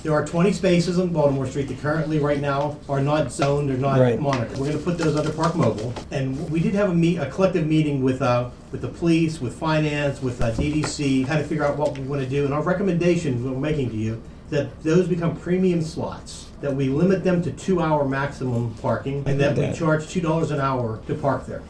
The Cumberland Mayor and City Council discussed Baltimore Street parking during yesterday evening’s Open Work Session.  The parking spots along Baltimore Street have been free to park in since its grand opening.
City Administrator Jeff Silka presented a plan to the Mayor and City Council to make each place a premium spot to ensure they are not monopolized…